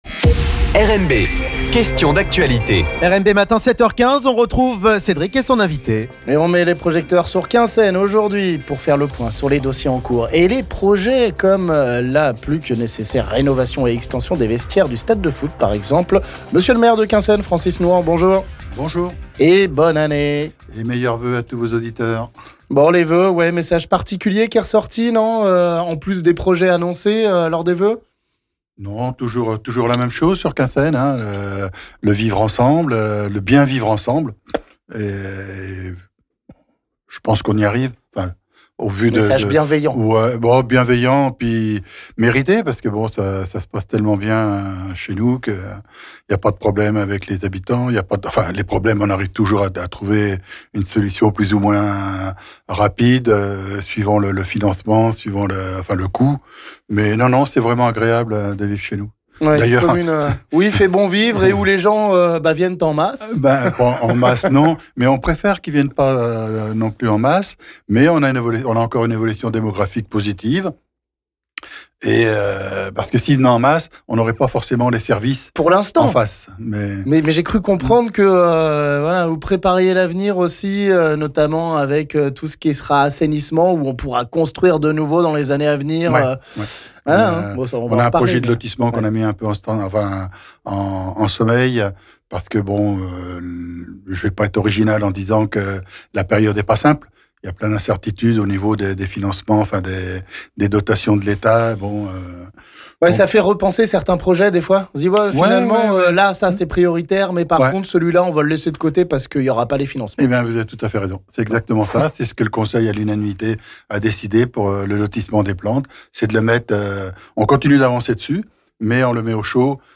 On fait le point sur l'actu et les projets de Quinssaines avec son maire Francis Nouhant qui est notre invit� - L'Invit� du Jour